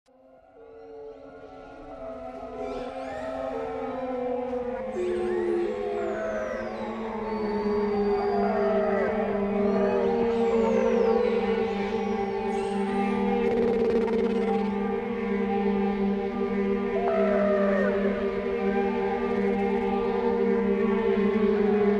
Звуки китов
Звуки серых китов в дикой природе